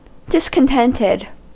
Ääntäminen
IPA : /ˌdɪskənˈtɛntɛd/